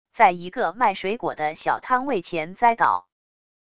在一个卖水果的小摊位前栽倒wav音频生成系统WAV Audio Player